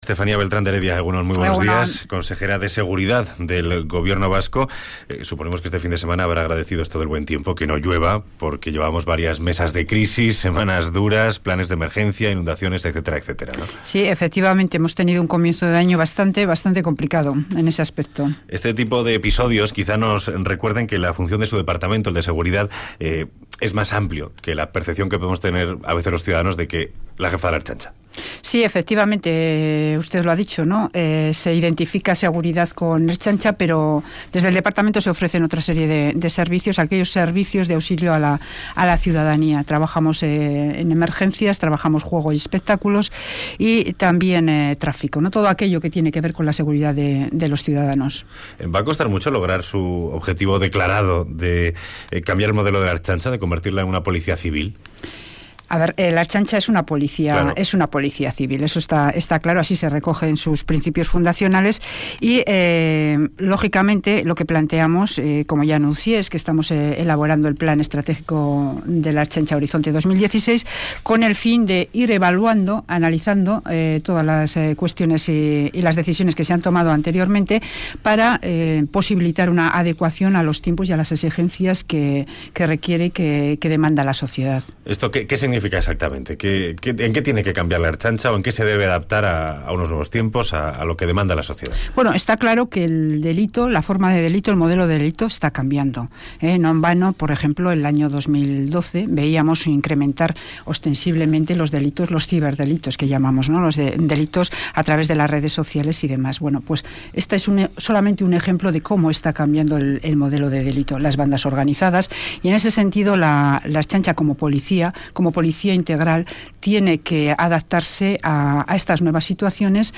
Entrevista a Estefanía Beltrán de Heredia, consejera de Seguridad